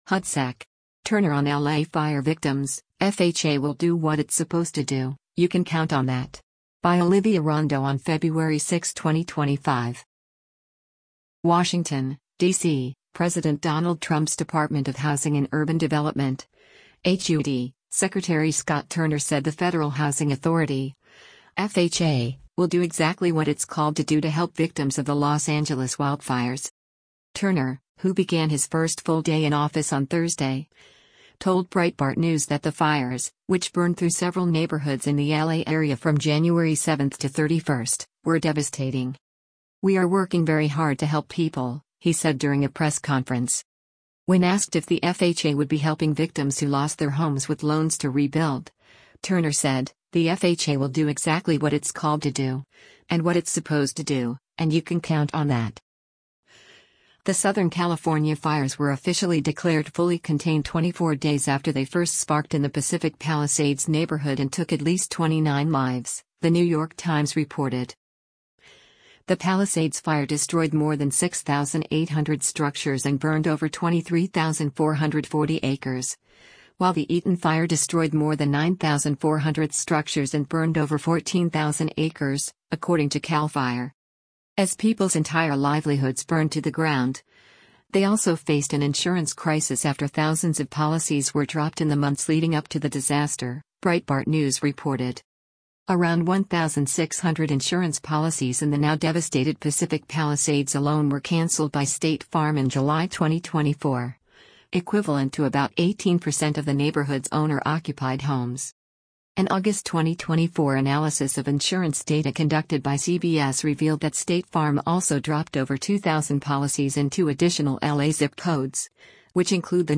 “We are working very hard to help people,” he said during a press conference.